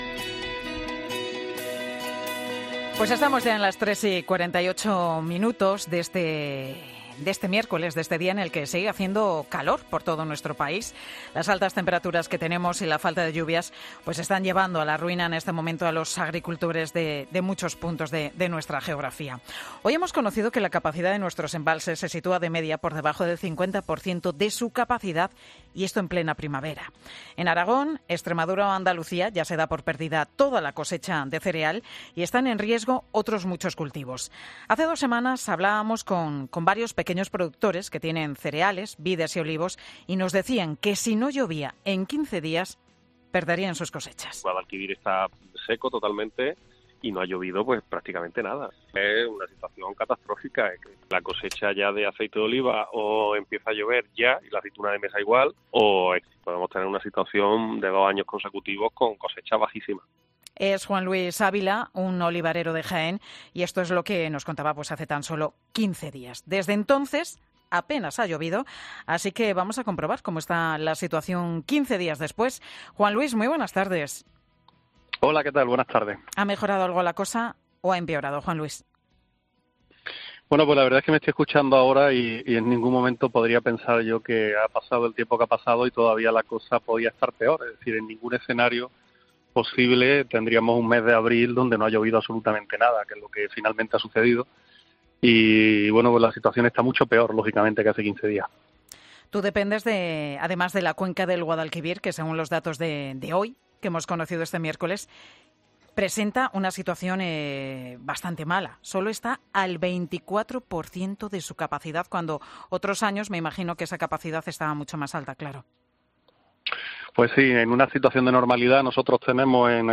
Un olivarero de Jaén da en 'Mediodía COPE' la solución a la persistente sequía